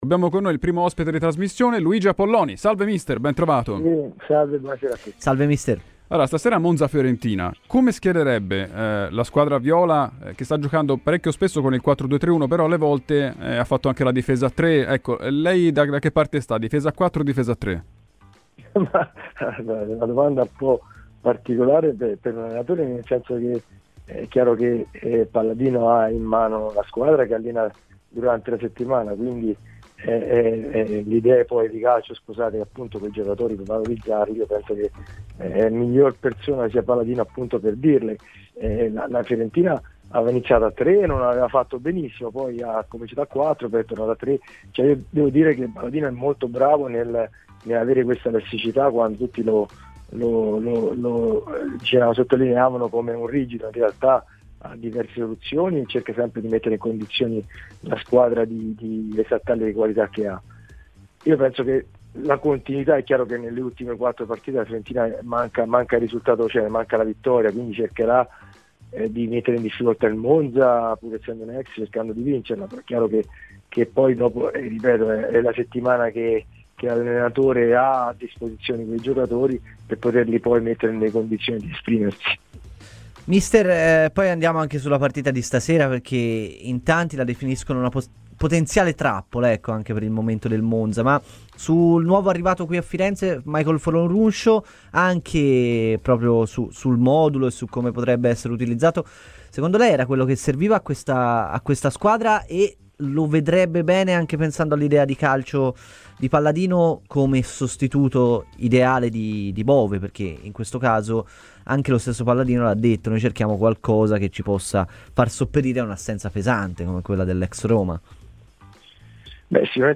L'ex calciatore oggi allenatore Luigi Apolloni è intervenuto a Radio FirenzeViola durante la trasmissione "Tempi Supplementari" per parlare dell'attualità di casa Fiorentina: "Palladino bravo ad esaltare la squadra.